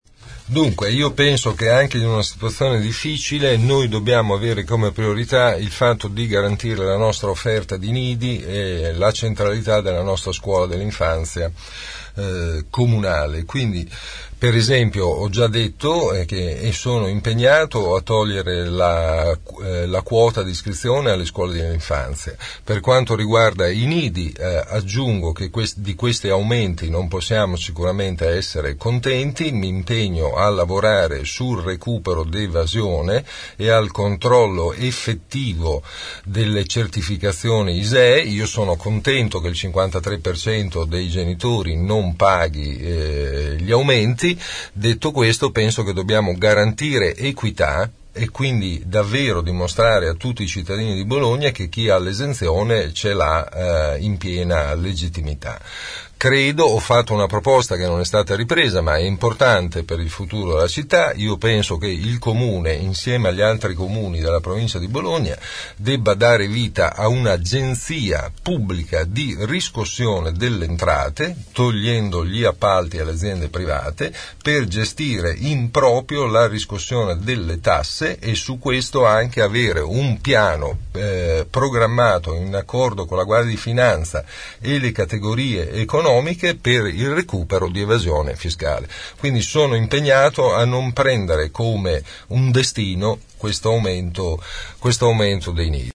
Il candidato del centro sinistra ospite dei nostri studi ha risposto alle nostre domande e a quelle degli ascoltatori.